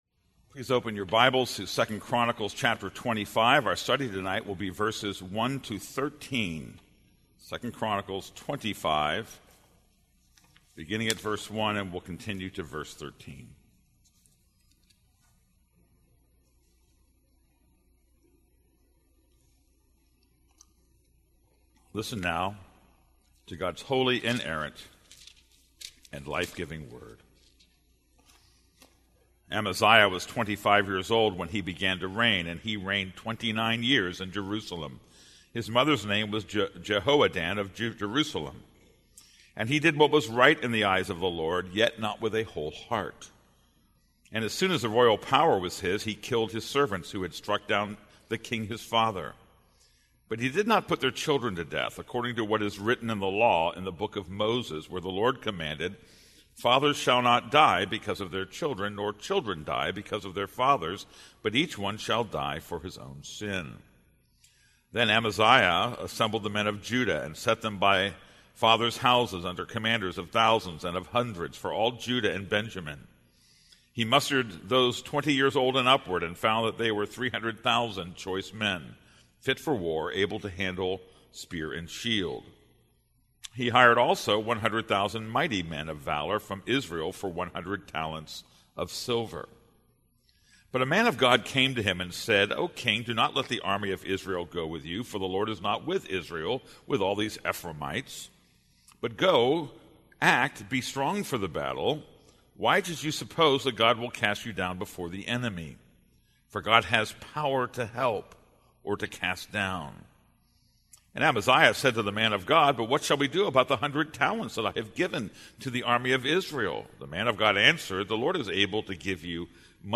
This is a sermon on 2 Chronicles 25:1-13.